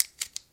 工具 剃刀片 Out03
描述：正在处理盒式切割机的声音。 此文件已标准化，大部分背景噪音已删除。没有进行任何其他处理。
Tag: 切割机 缩回 刀片 刀具 延伸 剃须刀